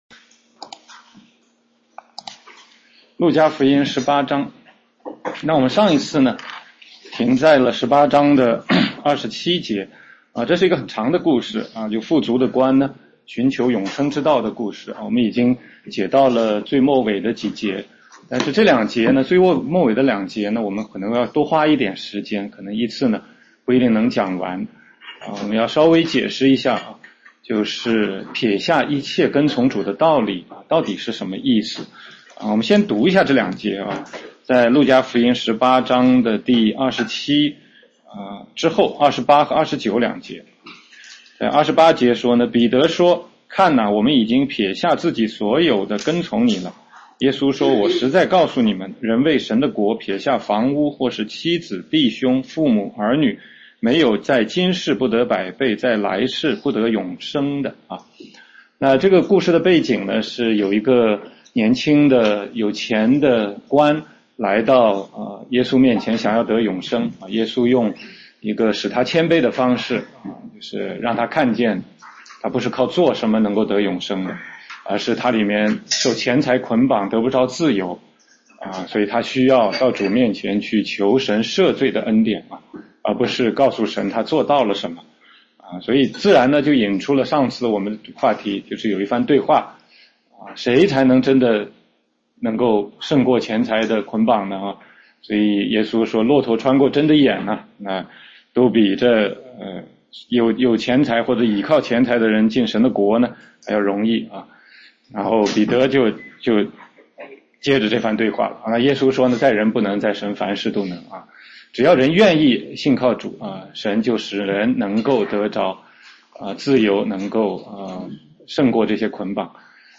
16街讲道录音 - 路加福音18章28-30节：撇下一切跟随主（1）